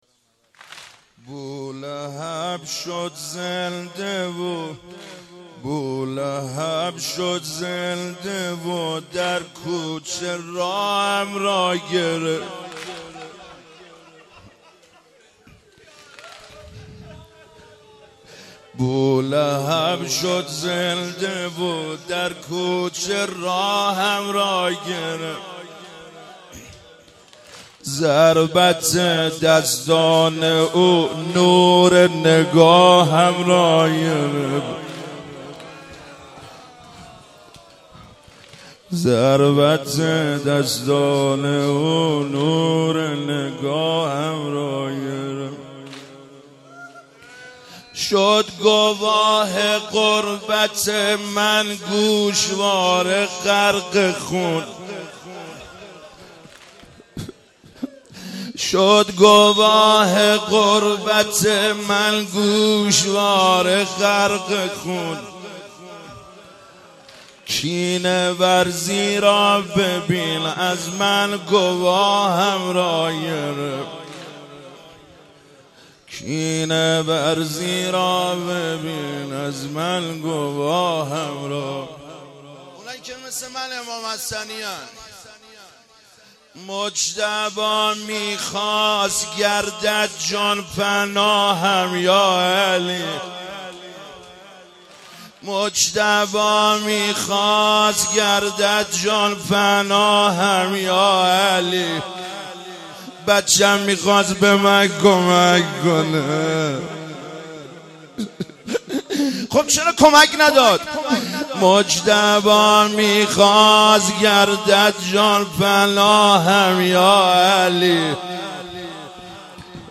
مراسم شب پنجم فاطمیه ۱۳۹۶
فاطمیه ۱۳۹۶